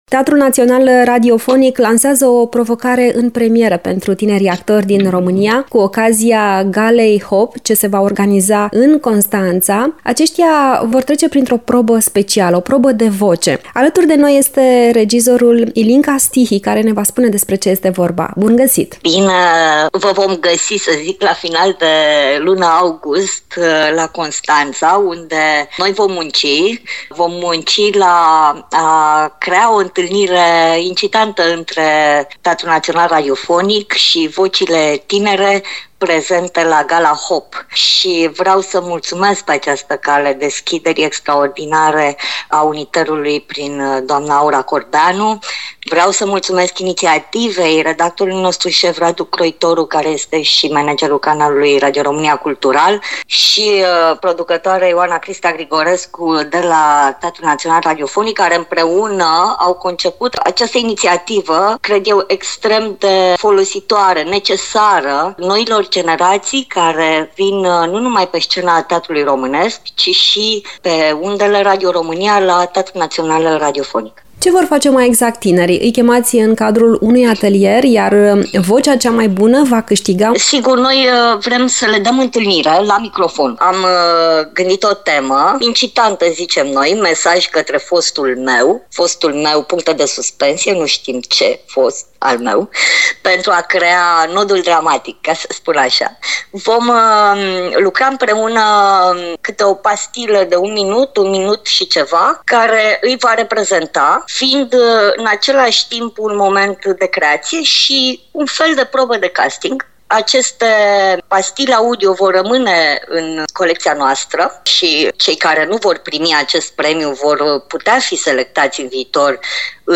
Aflăm detalii de la regizoarea